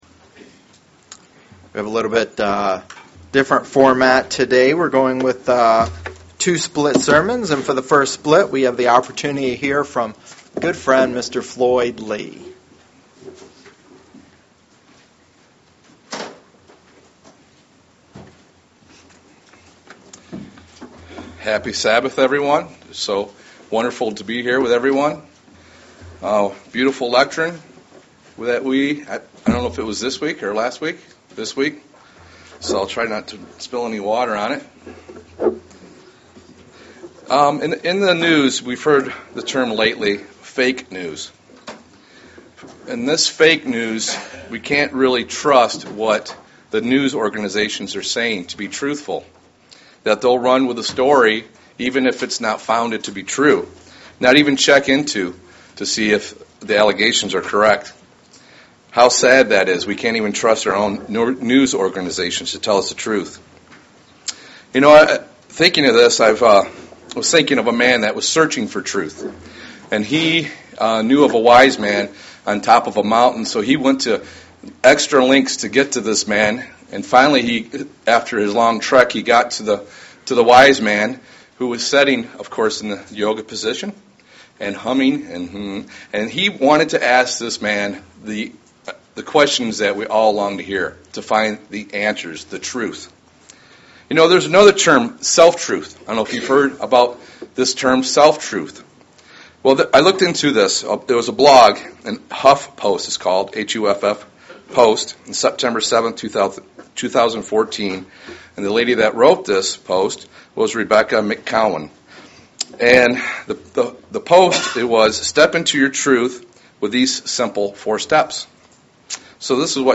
Sermons
Given in Lansing, MI